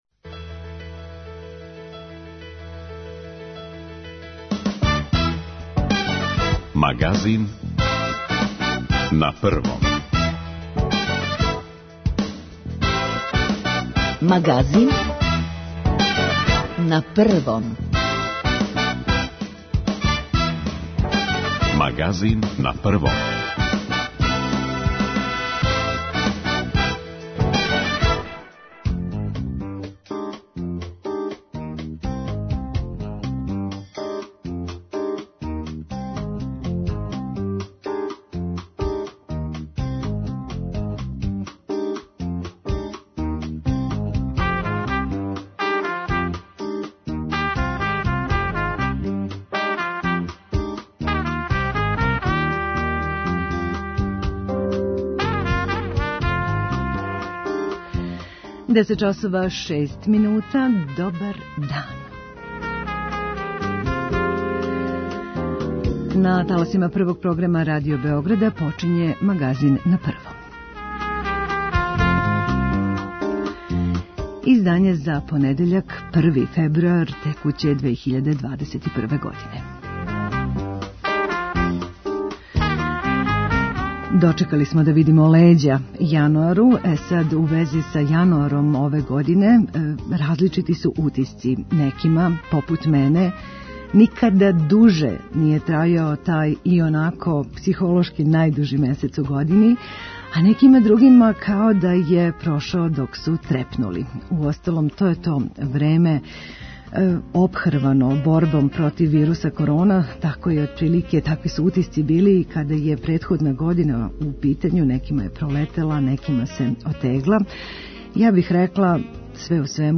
Извор: Радио Београд 1